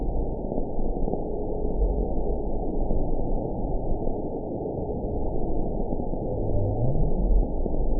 event 919715 date 01/18/24 time 05:31:39 GMT (1 year, 3 months ago) score 9.27 location TSS-AB09 detected by nrw target species NRW annotations +NRW Spectrogram: Frequency (kHz) vs. Time (s) audio not available .wav